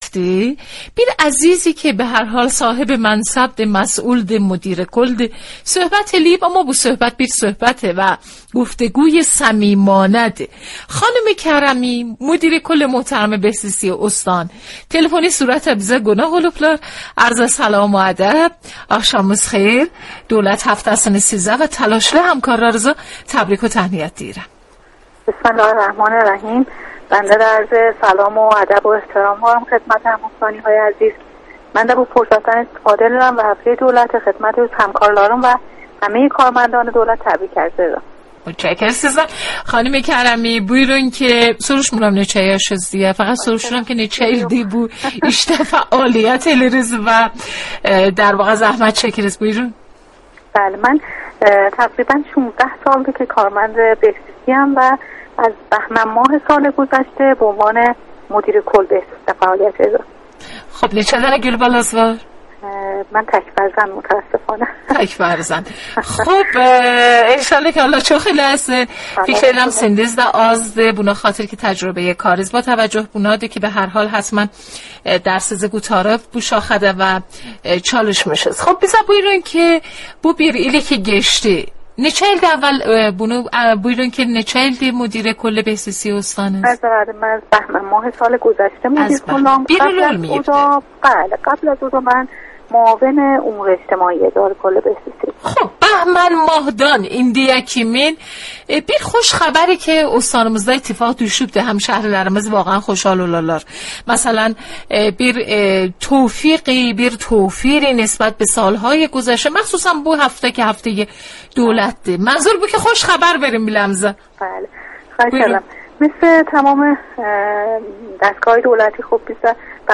همزمان با گرامیداشت هفته دولت مدیرکل بهزیستی استان زنجان با رادیو زنجان (برنامه رادیویی آخشاملار) مصاحبه کرد.